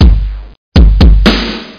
DRUM.mp3